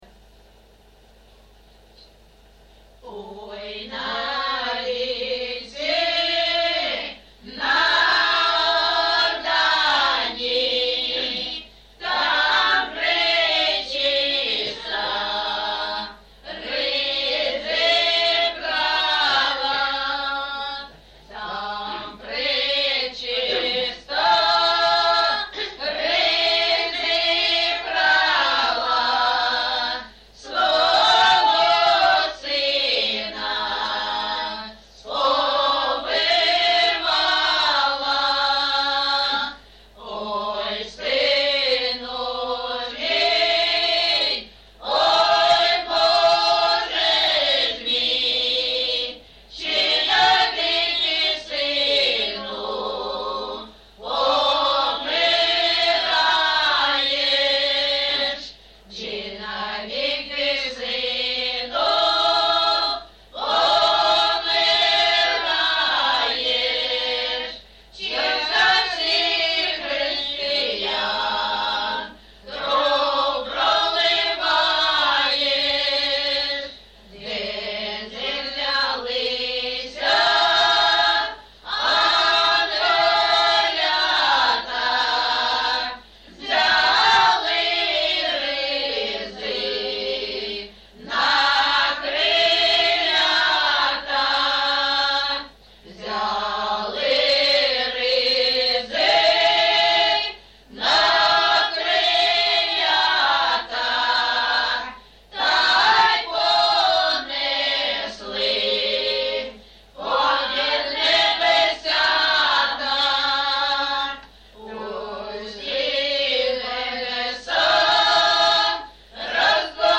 ЖанрКолядки
Місце записус-ще Щербинівка, Бахмутський район, Донецька обл., Україна, Слобожанщина